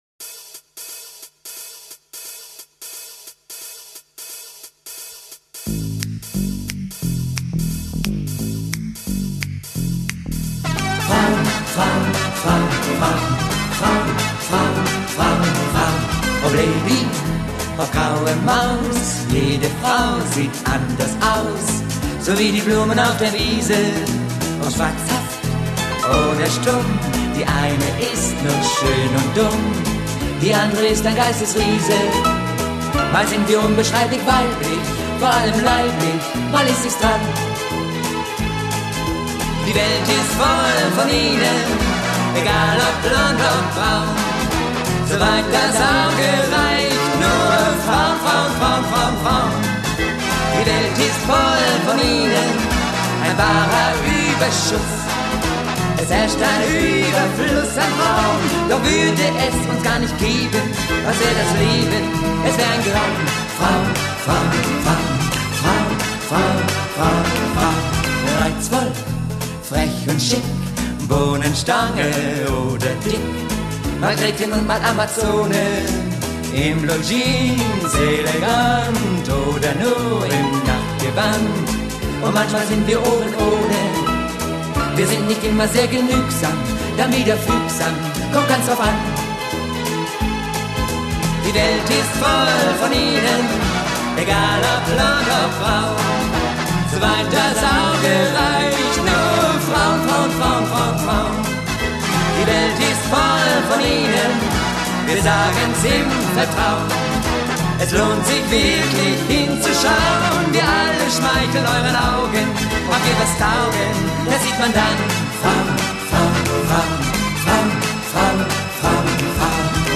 07 Quickstep